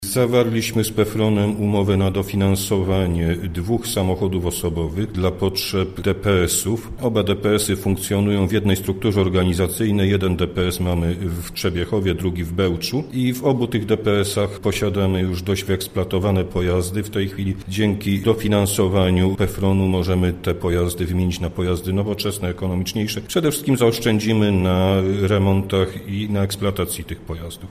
– Chodzi o dofinansowanie zakupu samochodów do przewozu osób niepełnosprawnych – mówi Dariusz Wróblewski, starosta zielonogórski: